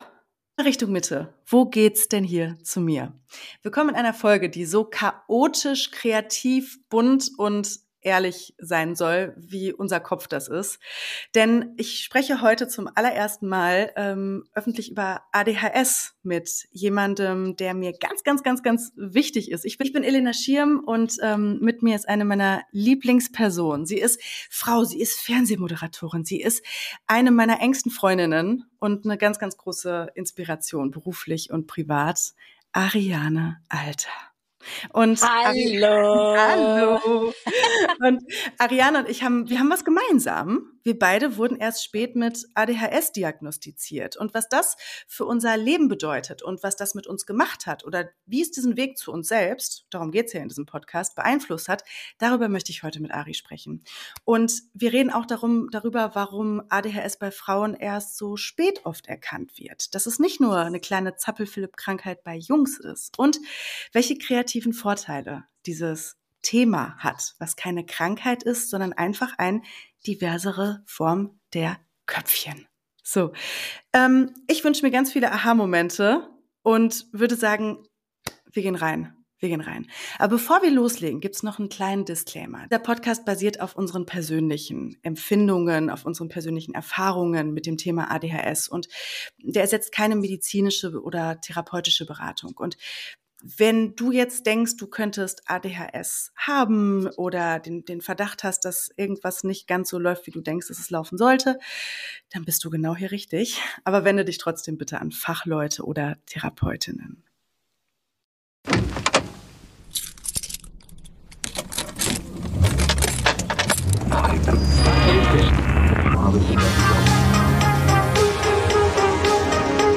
Beschreibung vor 8 Monaten Was passiert, wenn zwei kreative Frauen zusammensitzen, die beide erst spät ihre ADHS-Diagnose erhalten haben – und plötzlich merken: Oh wow, das erklärt ALLES? In dieser Folge spreche ich mit meiner Freundin und Moderatorin Ariane Alter über das wilde Innenleben von ADHS: Von Aufräumkriegen über Affenhirne bis hin zu Coachings, Beziehungskrisen und Medikation. Wir nehmen euch mit hinter die Kulissen unseres Denkens – ehrlich, humorvoll und absolut ohne Filter.